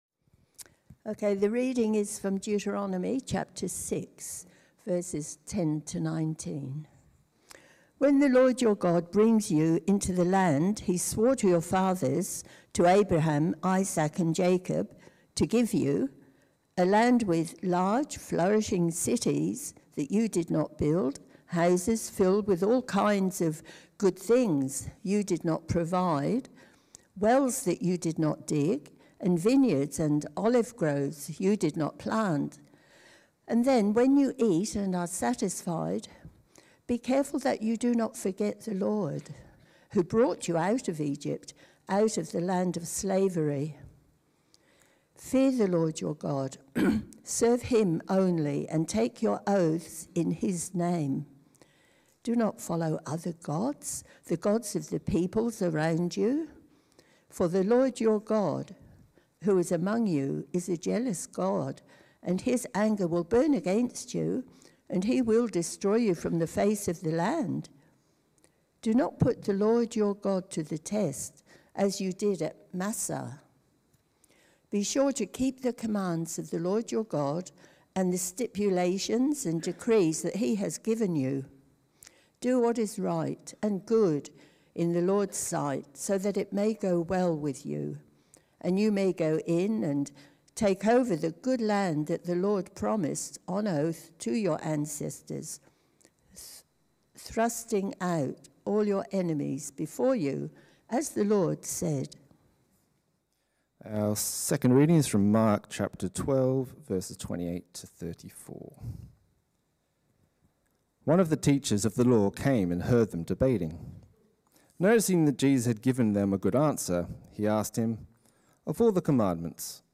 A message from the series "Committed."